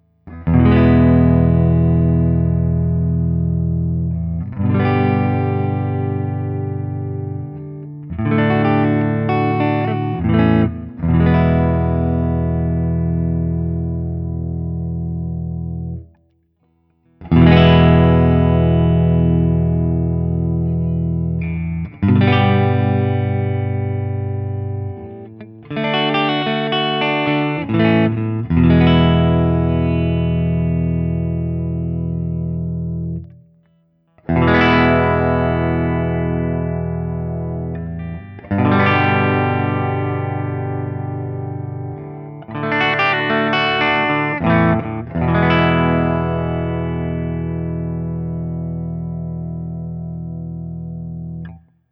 ODS100 Clean
Open Chords #1
As usual, for these recordings I used my normal Axe-FX III  setup through the QSC K12 speaker recorded direct into my Mac Pro using Audacity.
For each recording I cycle through the neck pickup, both pickups, and finally the bridge pickup.
Guild-Nightbird-DX-ODS100-Open1.wav